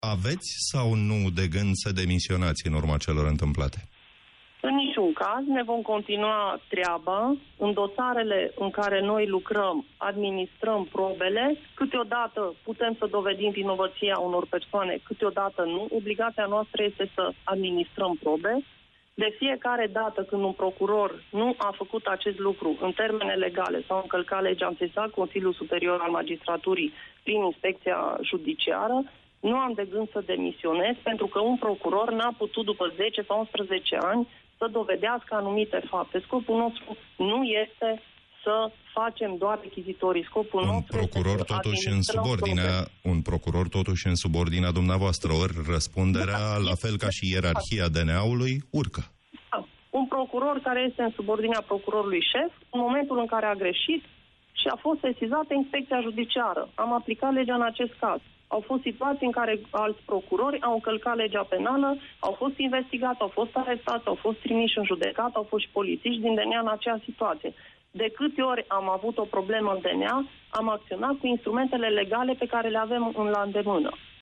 Procurorul șef al Direcției Naționale Anticorupție, Laura Codruța Kovesi, a declarat în exclusivitate la Europa FM că nu are de gând să demisioneze pentru că ”un procuror nu a putut după 10 sau 11 ani să dovedească anumite fapte.”